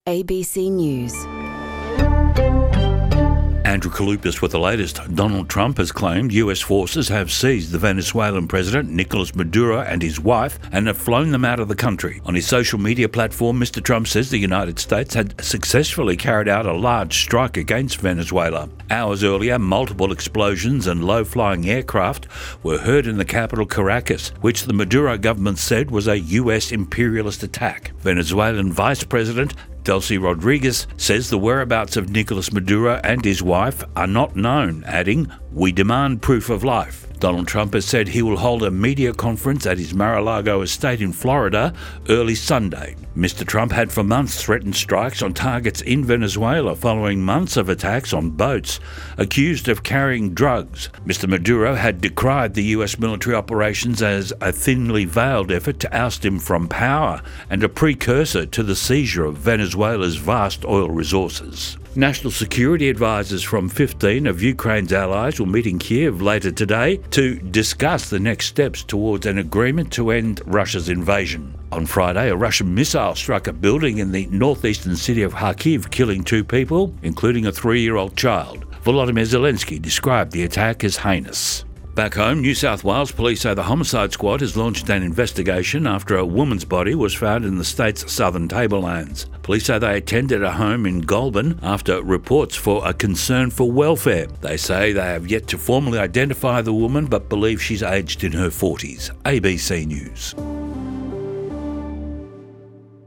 ABC News 33 subscribers updated 4h ago Subscribe Subscribed Play Playing Share Mark all (un)played …